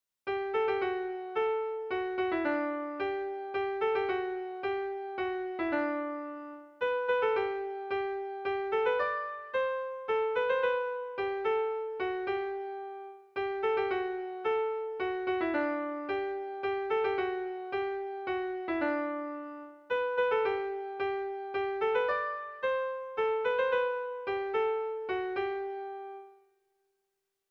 Bertso melodies - View details   To know more about this section
Irrizkoa
Zortziko handia (hg) / Lau puntuko handia (ip)
ABAB